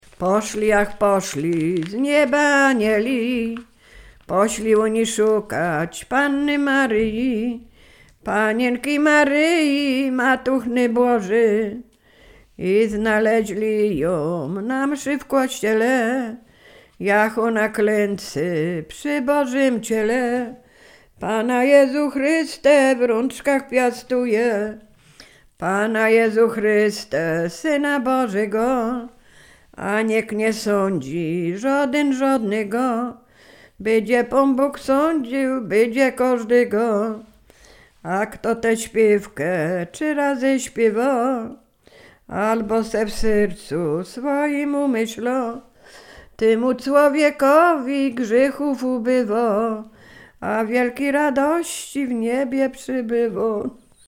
Sieradzkie
nabożne apokryficzne modlitwy